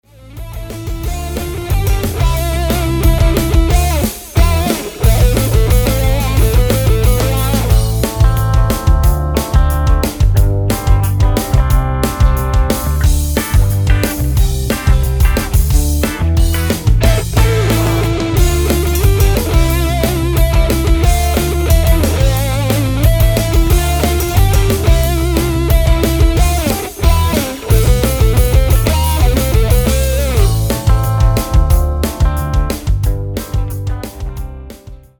Всё, кроме барабасов ;) сделано через ПОД.